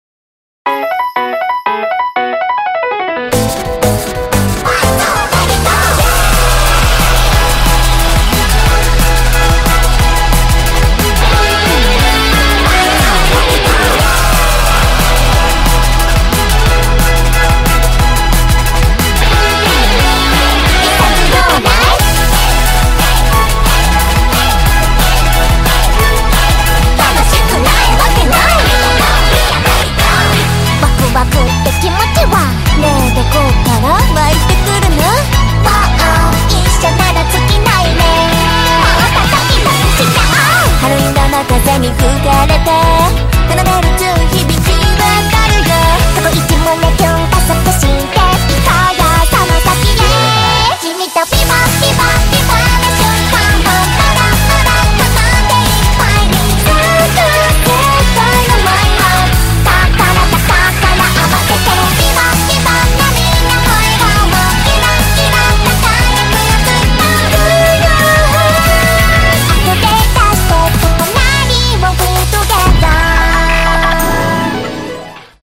Но музыка веселая и смешная .